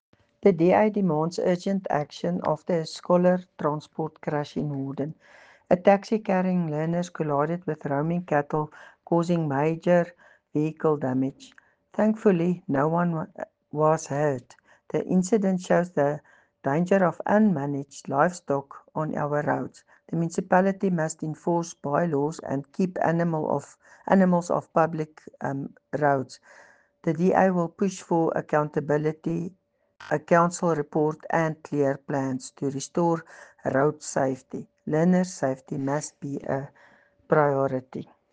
English and Afrikaans soundbites by Cllr Doreen Wessels and